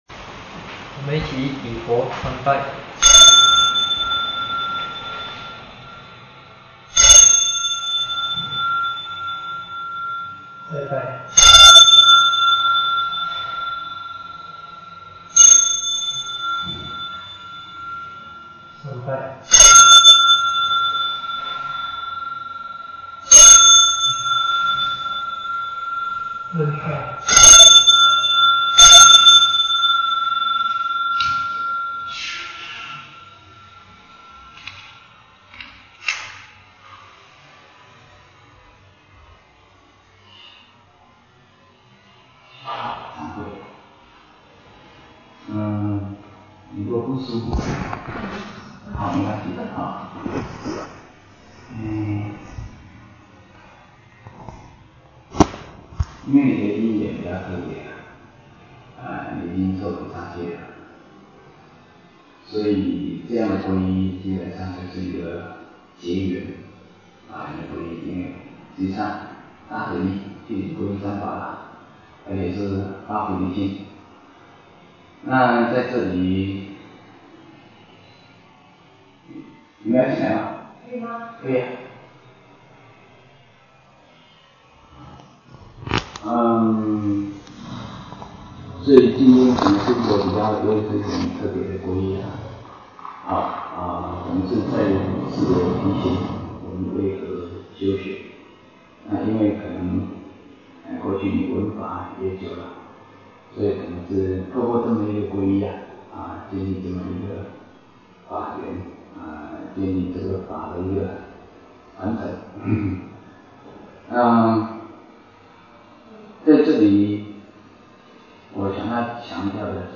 三皈依开示04.mp3